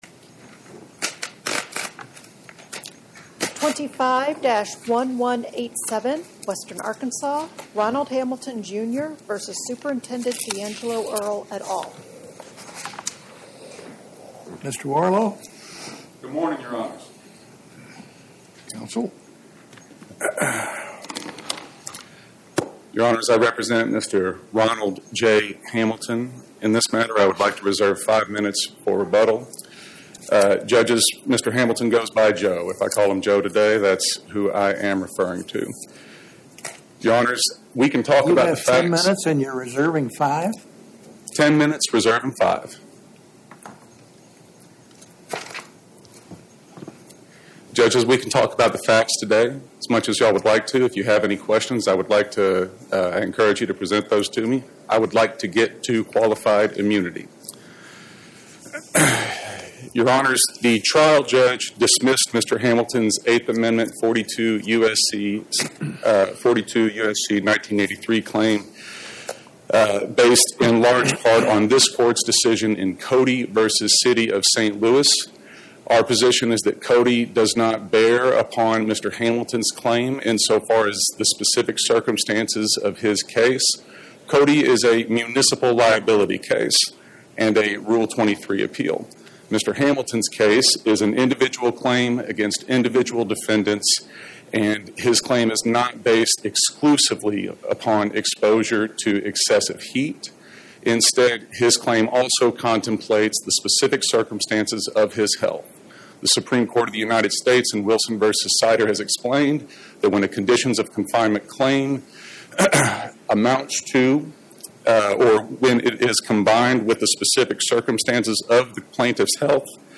Oral argument argued before the Eighth Circuit U.S. Court of Appeals on or about 01/13/2026